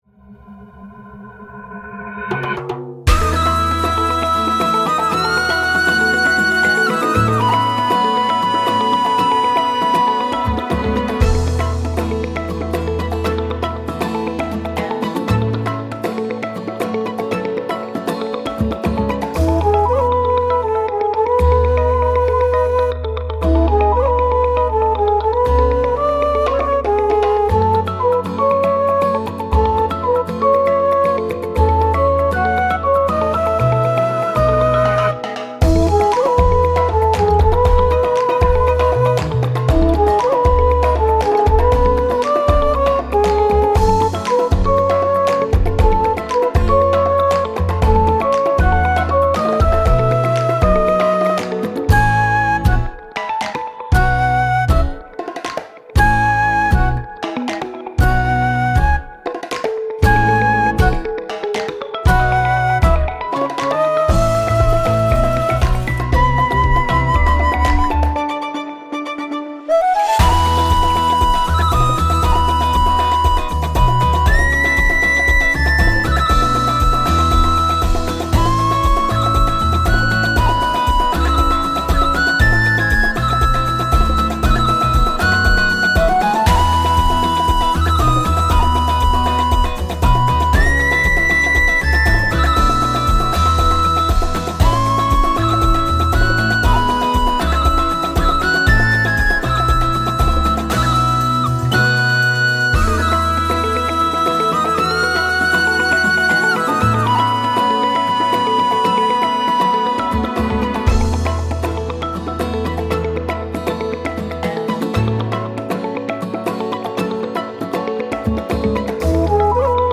BPM 118
チェロ
バイオリン ヴィオラ
アコースティックギター